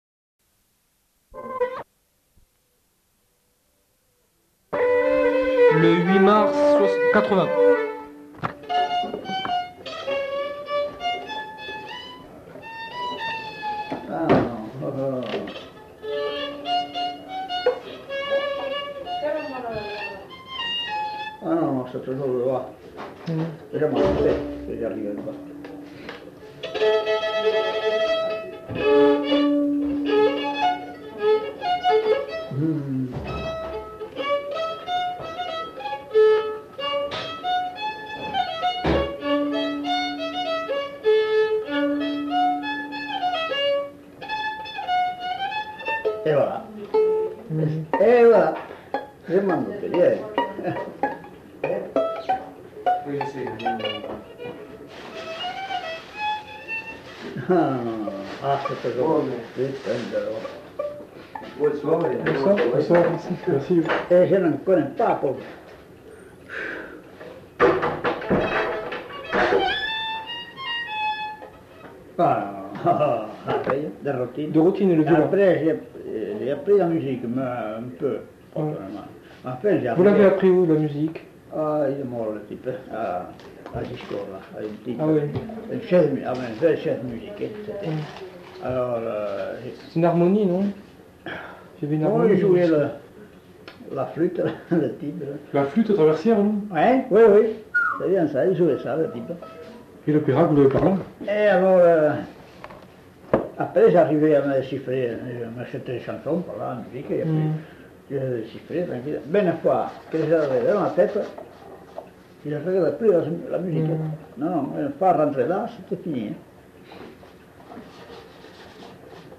Lieu : Saint-Michel-de-Castelnau
Genre : morceau instrumental
Instrument de musique : violon
Danse : congo